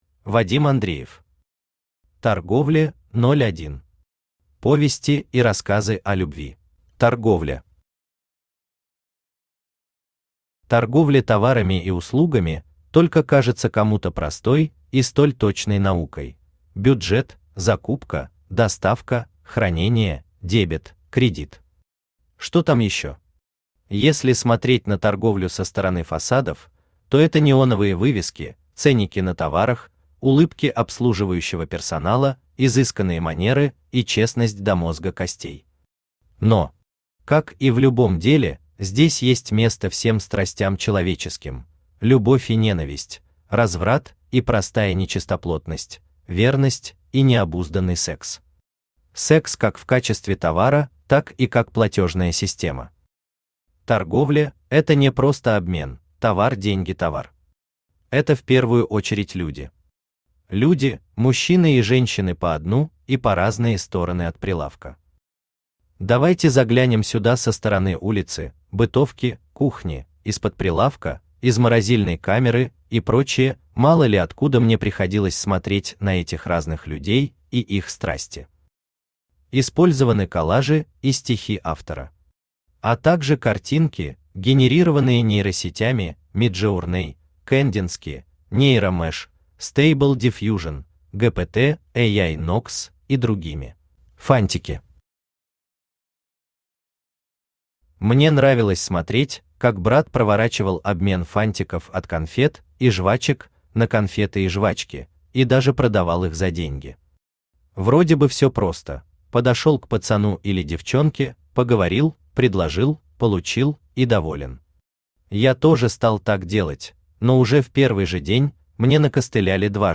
Повести и рассказы о любви Автор Вадим Андреев Читает аудиокнигу Авточтец ЛитРес.